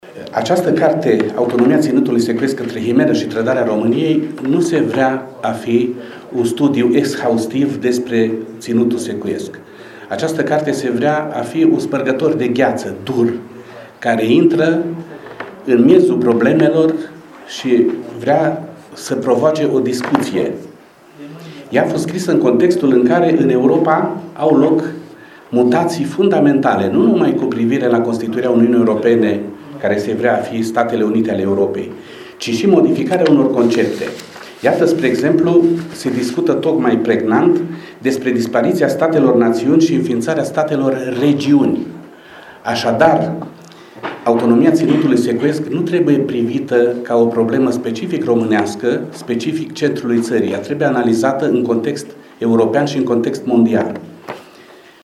În cadrul manifestărilor ce au loc la XIII-a ediţie a Universităţii de Vară a Românilor de Pretutindeni a fost prezentată, azi, cartea „Autonomia Ţinutului Secuiesc între himeră şi trădarea României”, scrisă de Vasile Moiş.
Autorul a subliniat faptul că volumul trage un semnal de alarmă dur asupra pretenţiilor de autonomie teritorială pe criterii etnice: